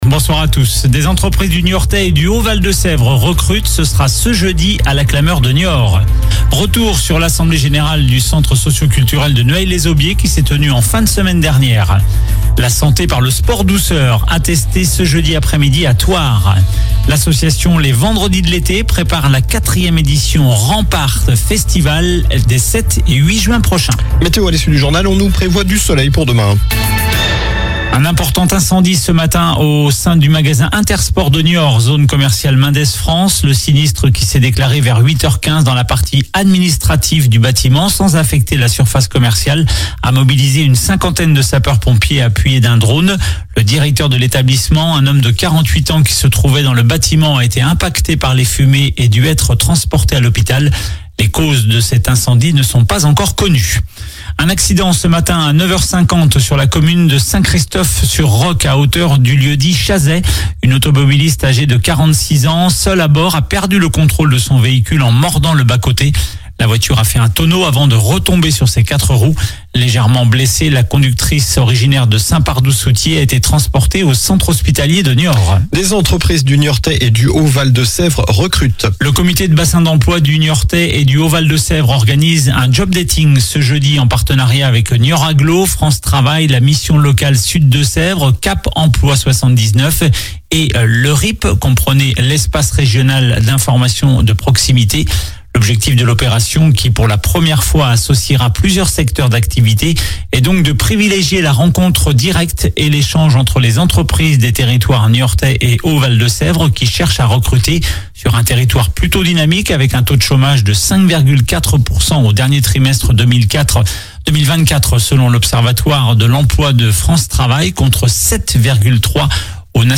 infos locales 15 avril 2025